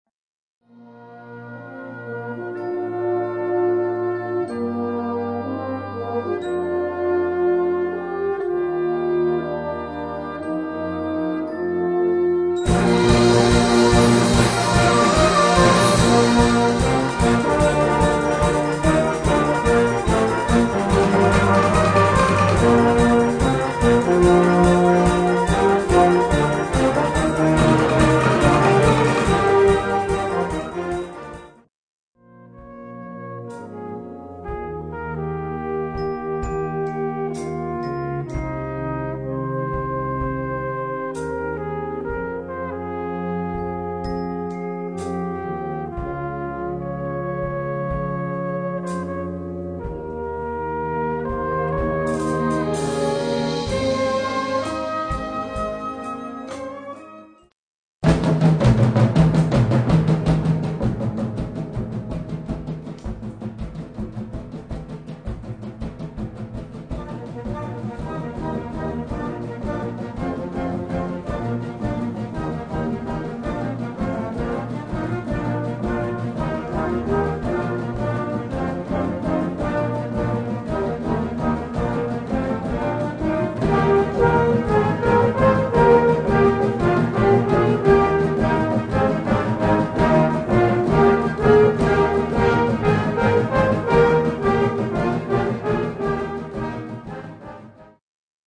Gattung: Konzertwerk
Besetzung: Blasorchester